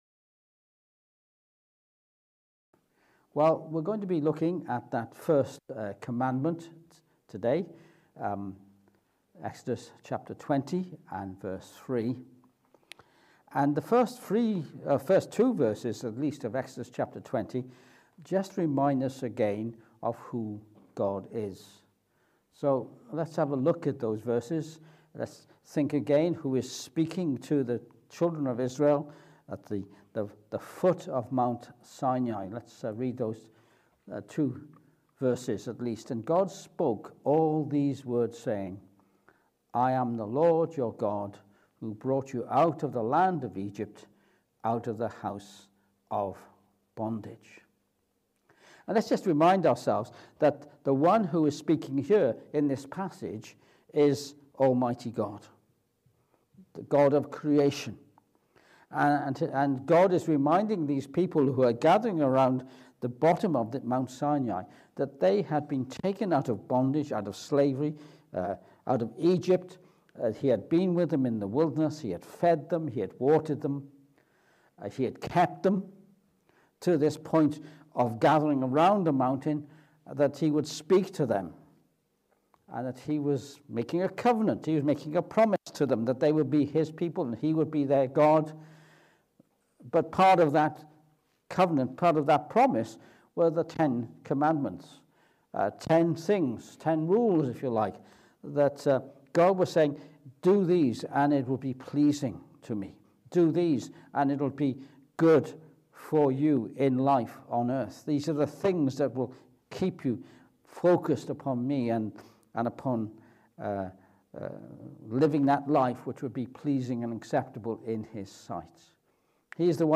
Exodus 20:3 Service Type: Morning Service We consider the first of the Ten Commandments from Exodus 20:3